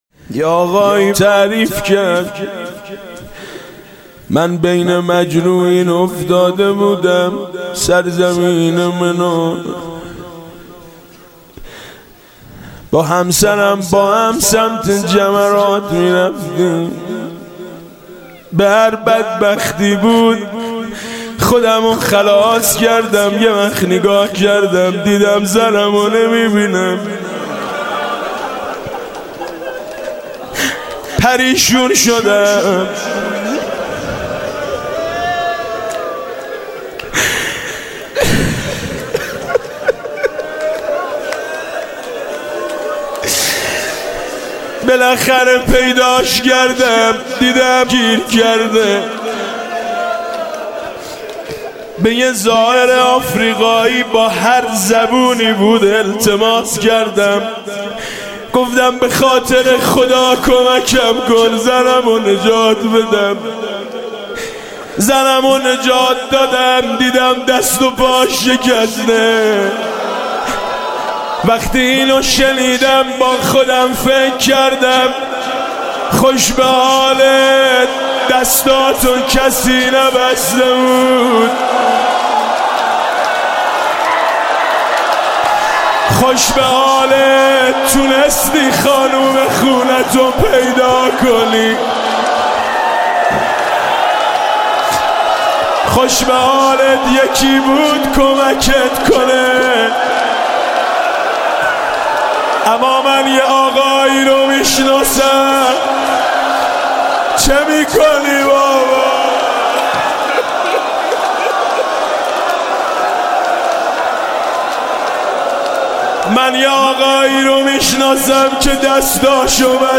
صوت مراسم شب دوم محرم ۱۴۳۷ هیئت میثاق با شهدا ذیلاً می‌آید: